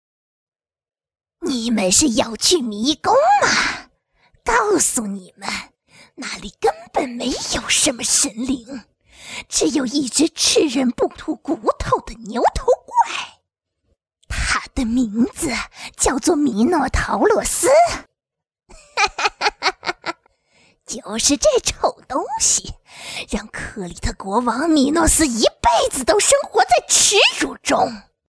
女声